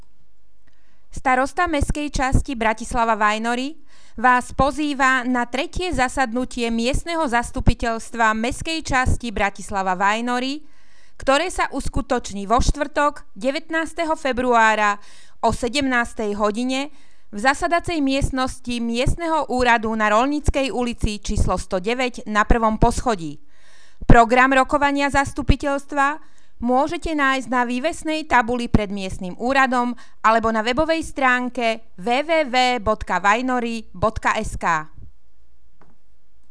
Hlásenie miestneho rozhlasu 18., 19.2.2015